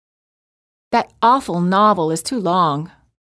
• ストレスをかける単語の主要な母音を長く発音する
※当メディアは、別途記載のない限りアメリカ英語の発音を基本としています